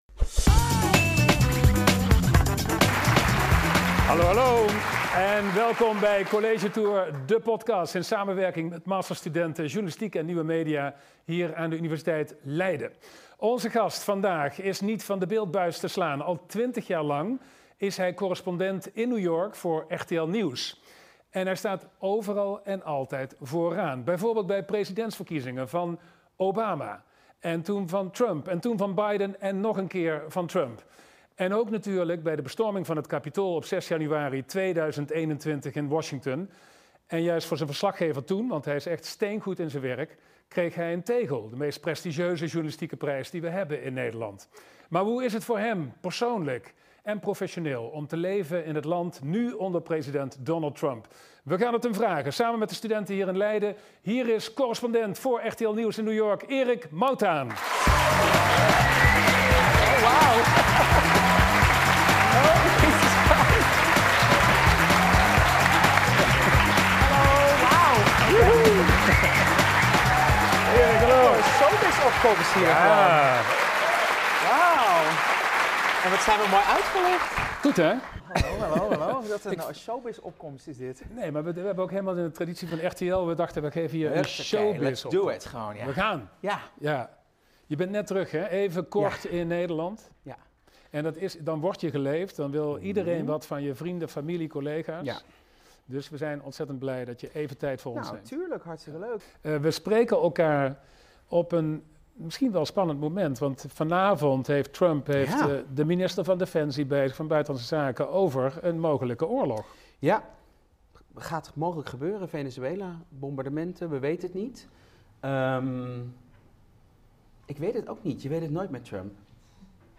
In de nieuwste aflevering van College Tour de podcast! gaat Twan Huys in gesprek met Erik Mouthaan, RTL-correspondent in New York.
Een gesprek over journalistiek, Amerika door de jaren heen en de inzichten die alleen tijd en ervaring kunnen opleveren. Dit interview met Erik Mouthaan werd opgenomen in collegezaal Lipsius 0.11 aan de Universiteit Leiden op 1 december 2025.